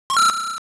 Sound effect of "Coin" in Wario Land: Super Mario Land 3.
WL_Coin.oga